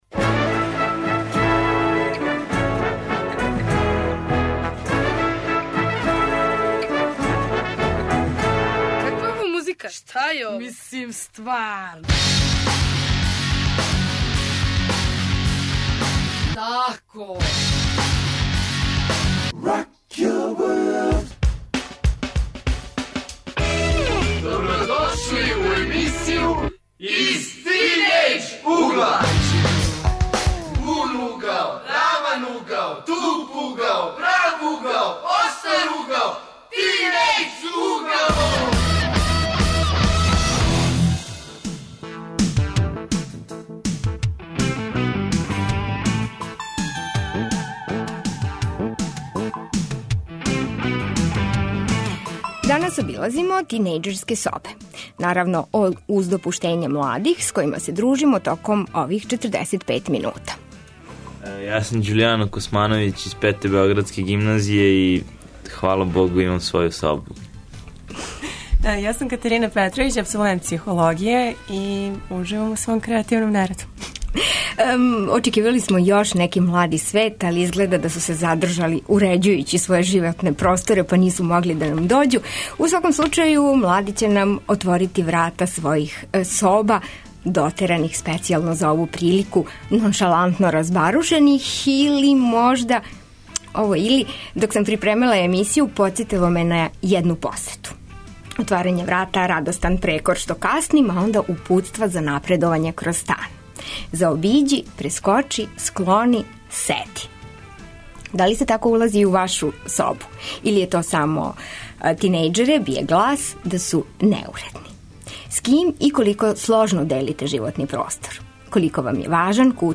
Говориће тинејџери